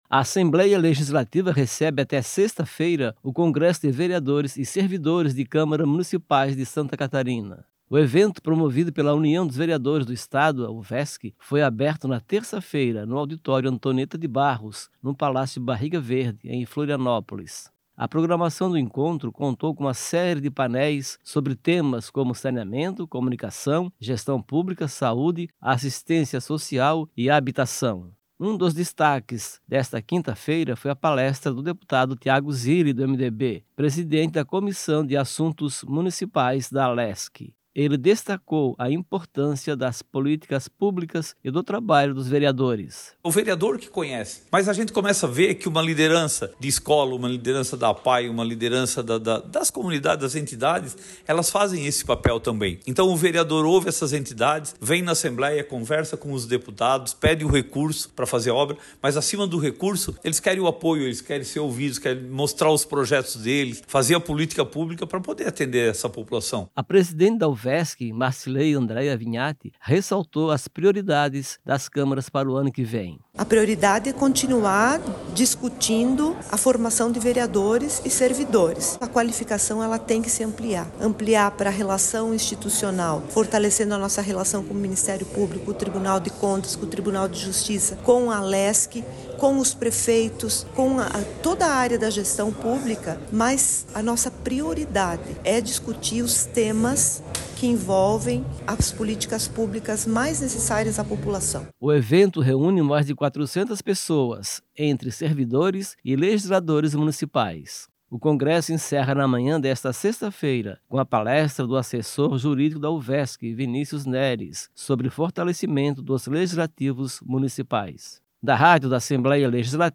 Entrevistas com:
- depuado Tigo Zilli (MDB);